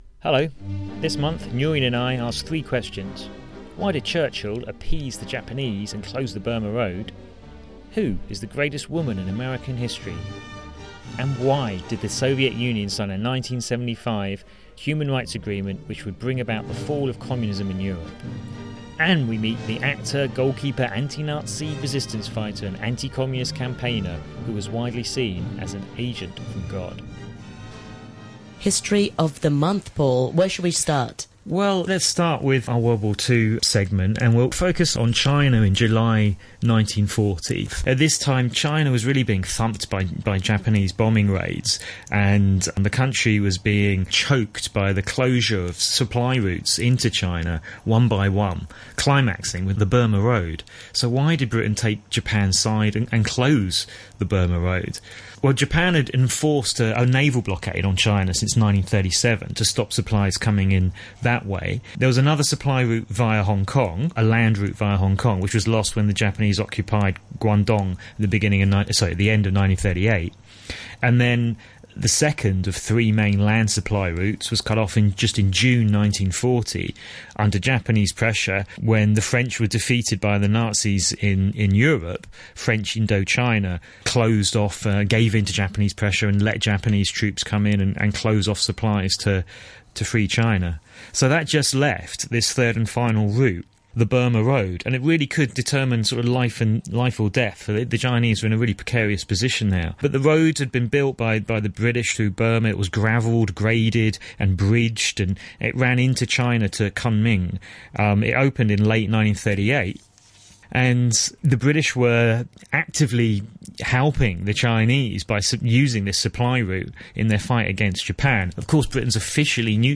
GO TO iTUNES / STITCHER AND SUBSCRIBE TO ‘THIS MONTH IN HISTORY’ TO RECEIVE EVERY DOWNLOAD DECEMBER: BATTLE OF HONG KONG 75th ANNIVERSARY SPECIAL Following a respectful nod to Pearl Harbor and its 75th anniversary, we canter through the Battle of Hong Kong. We are aided by an interview with a lady who was a young working woman at the time, caught in the heart of the action in Central Victoria